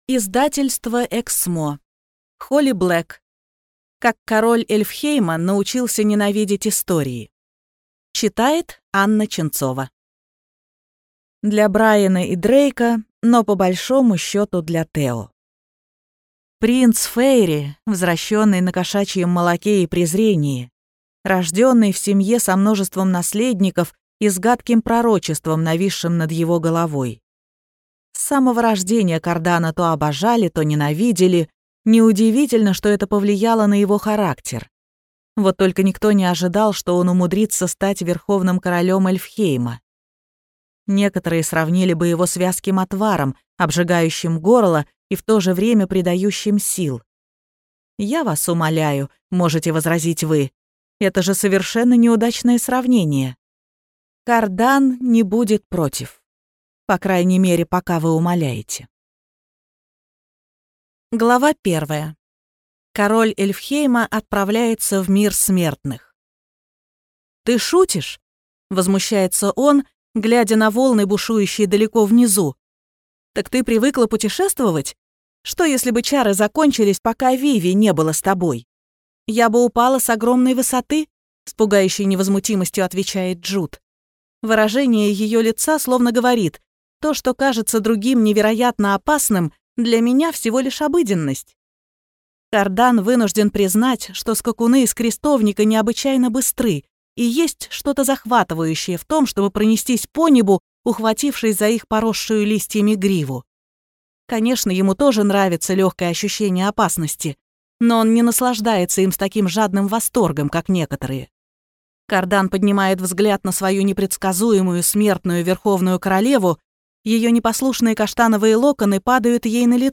Аудиокнига Как король Эльфхейма научился ненавидеть истории | Библиотека аудиокниг